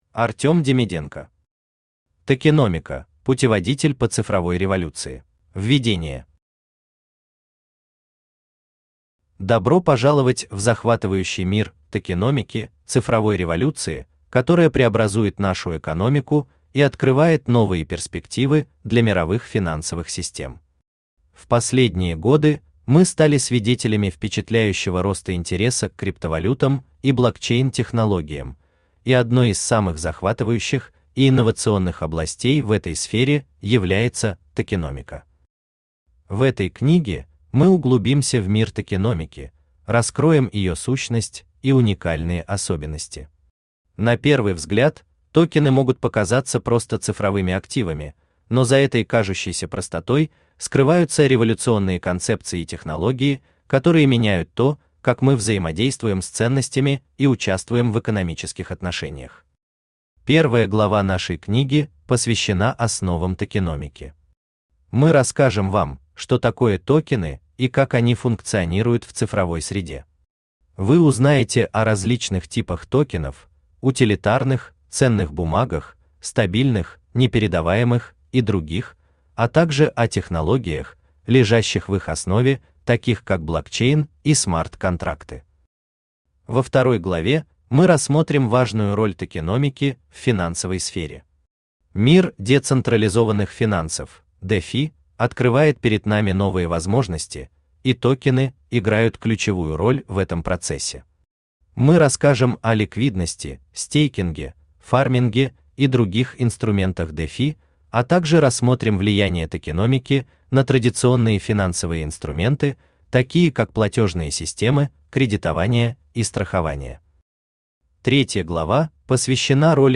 Aудиокнига Токеномика: Путеводитель по цифровой революции Автор Артем Демиденко Читает аудиокнигу Авточтец ЛитРес.